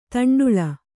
♪ taṇḍuḷa